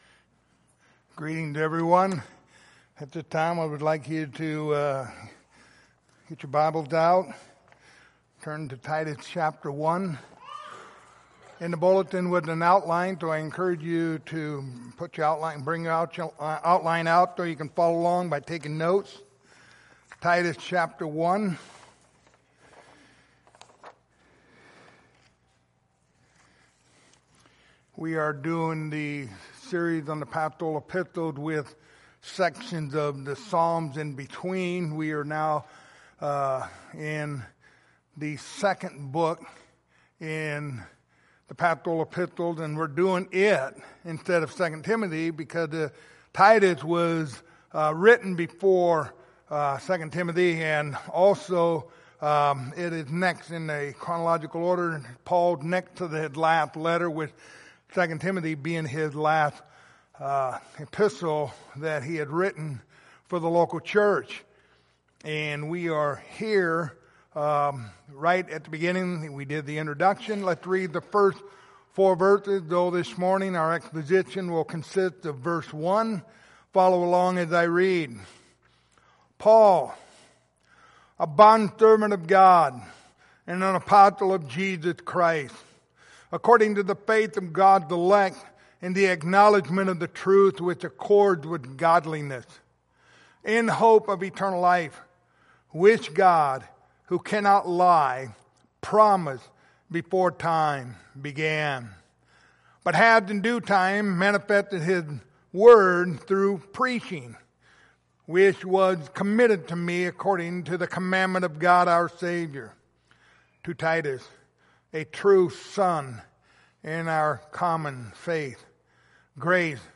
Passage: Titus 1:1 Service Type: Sunday Morning